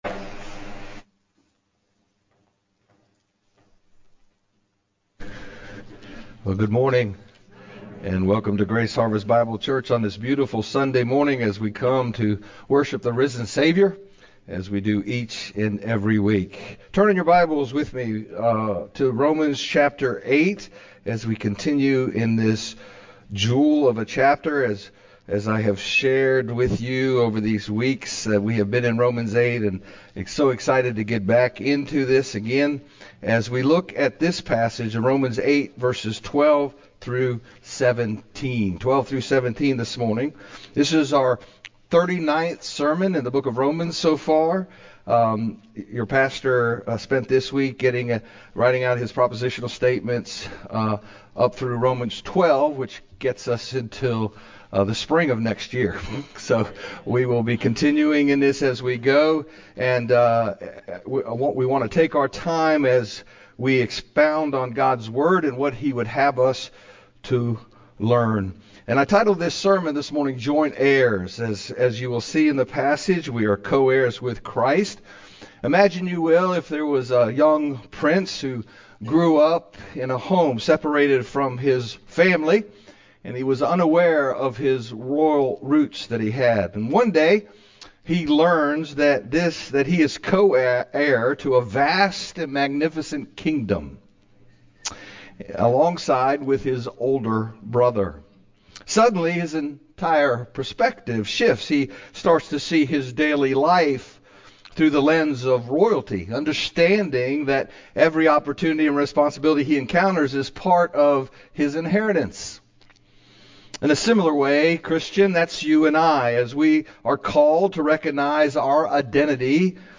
GHBC-Service-Joint-Heirs-Rom-812-17-CD.mp3